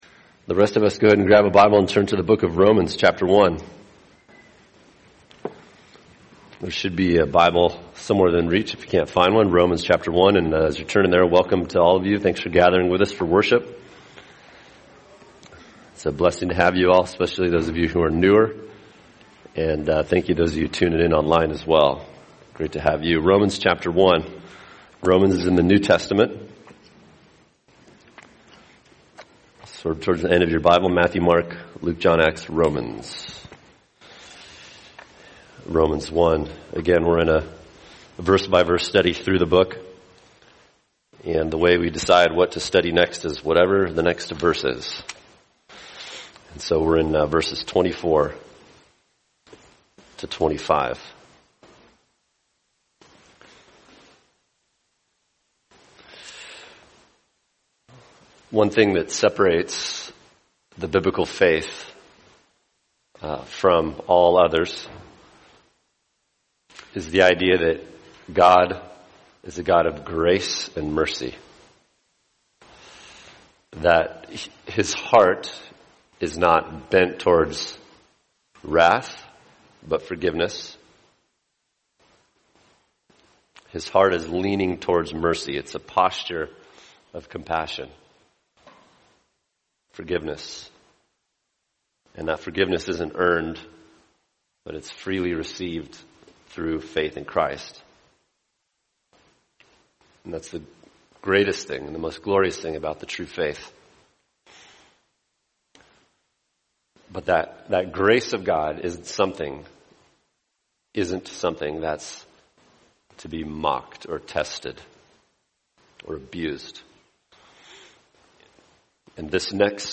[sermon] Romans 1:24-25 When God Lets You Have Your Way – Part 1 | Cornerstone Church - Jackson Hole